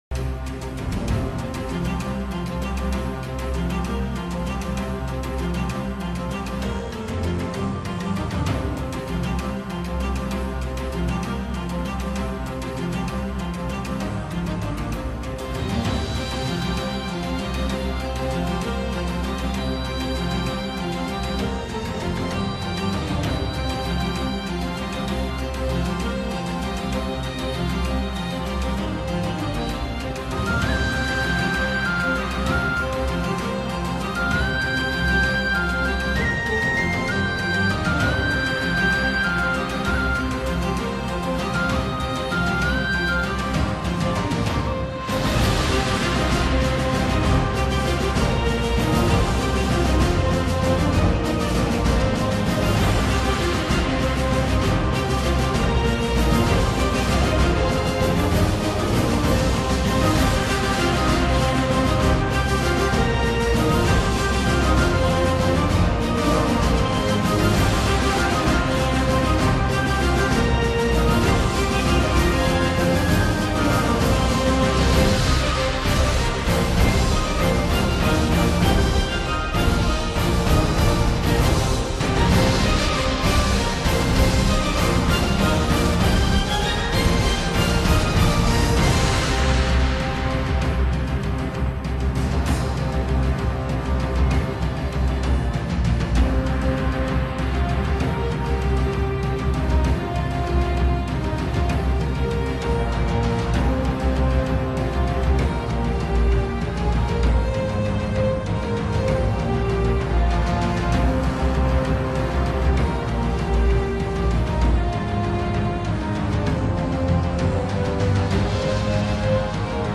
KEtmnp4L4NU_musica-de-piratasmusica-de-aventuras-de-piratas.mp3